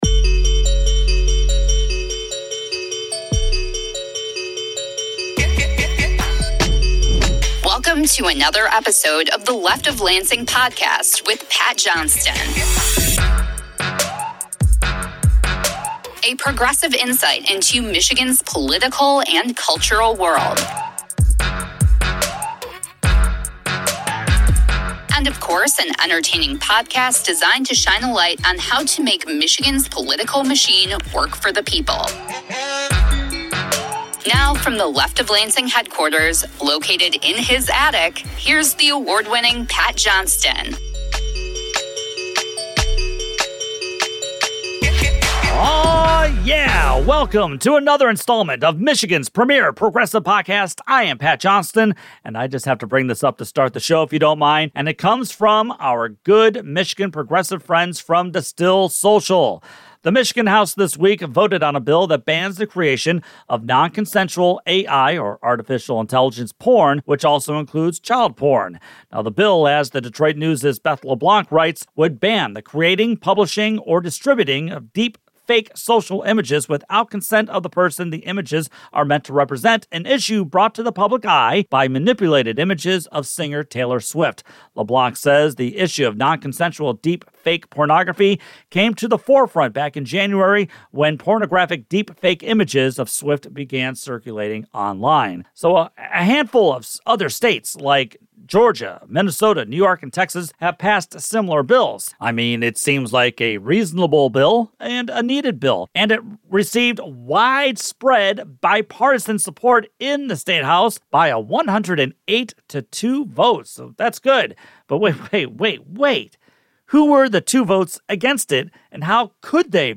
It's Episode 90 of Michigan's premier progressive podcast!